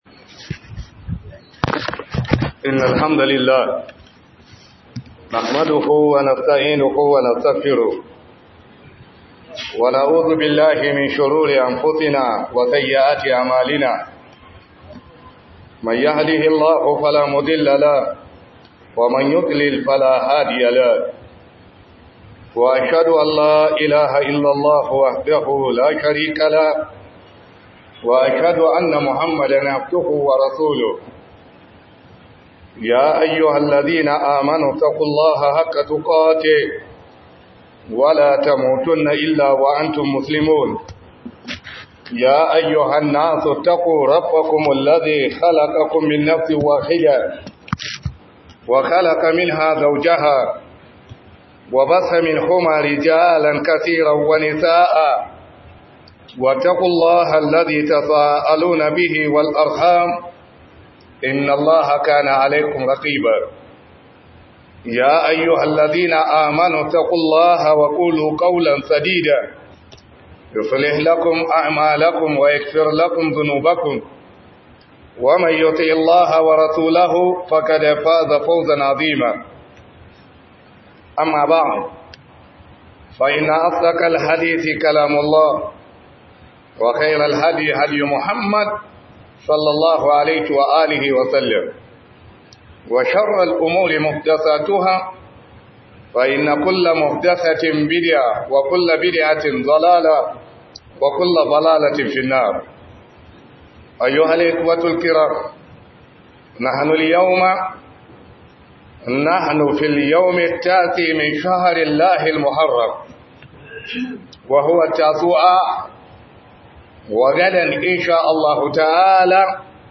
FALALAR_AZUMIN_RANAR_ASHURAA_2025_07 - Huduba